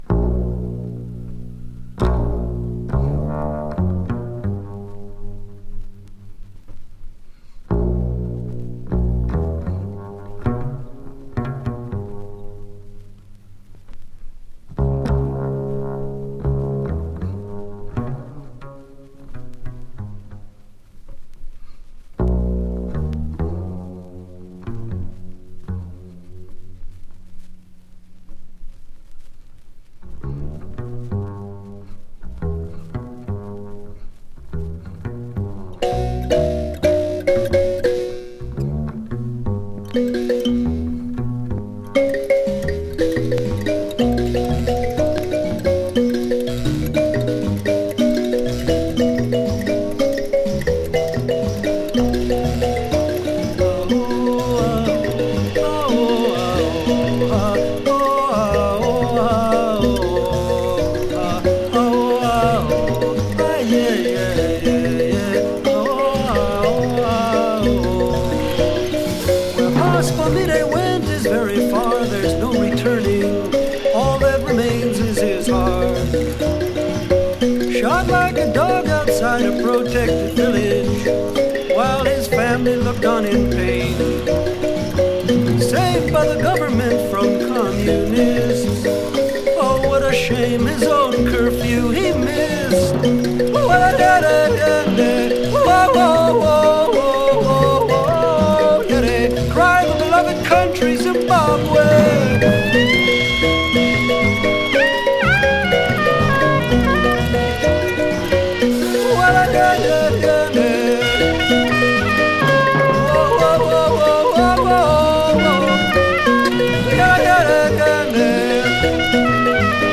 African Contemporary Folk！
ムビラ、アフリカン・パーカッションとアフリカン・チャントをフューチャーしたコンテンポラリーなフォーク・ナンバーを収録！
【AFRICA】【FOLK】